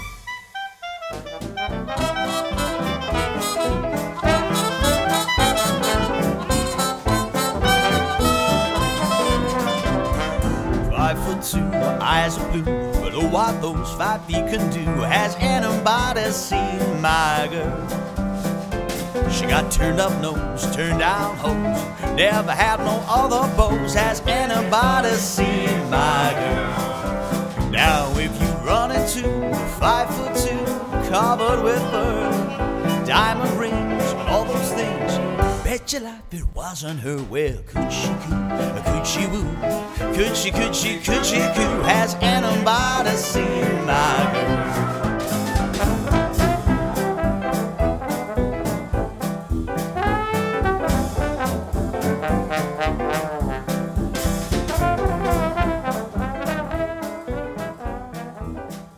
• Versatile, genre-hopping jazz ensemble
• Traditional jazz but with a modern twist
• Inspired by '20s/'30s New Orleans music